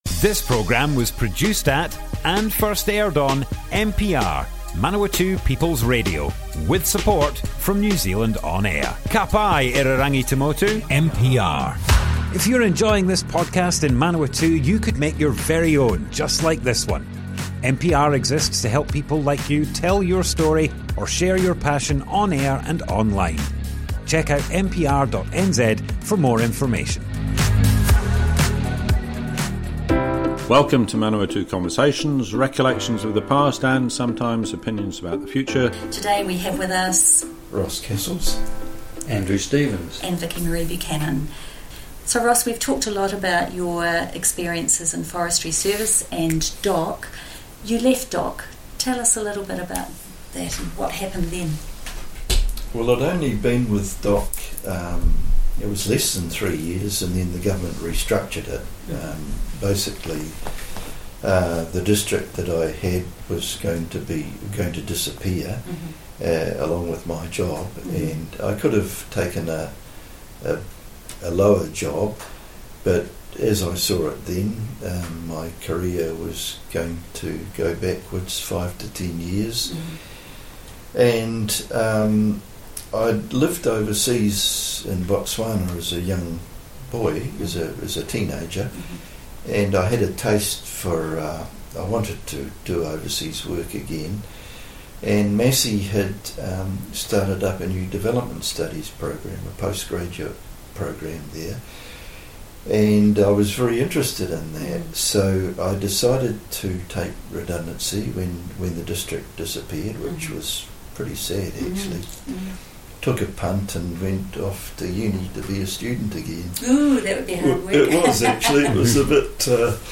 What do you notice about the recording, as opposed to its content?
Manawatu Conversations More Info → Description Broadcast on Manawatu People's Radio, 22nd November 2022.